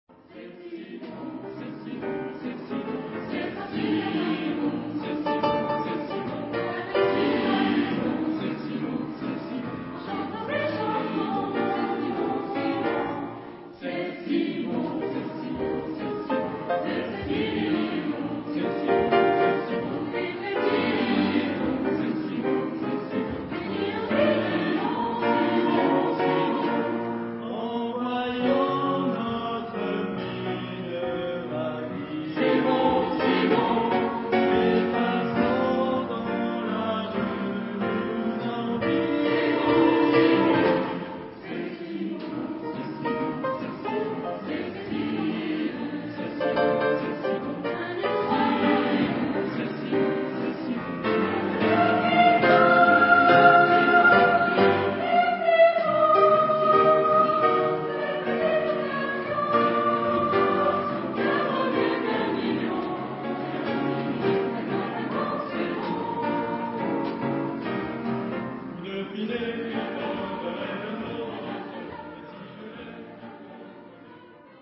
Genre-Style-Forme : Chanson d'amour
Type de choeur : SATB  (4 voix mixtes )